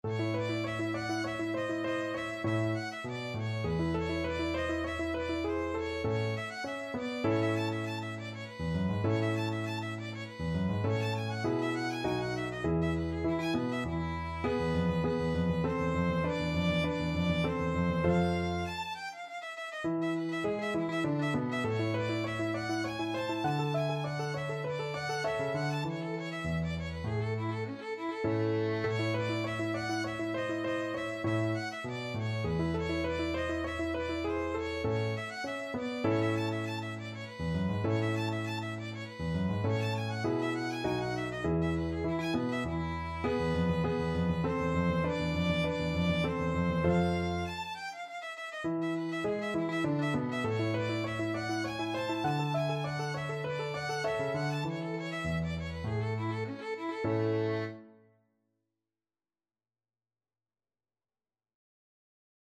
3/4 (View more 3/4 Music)
Classical (View more Classical Violin Music)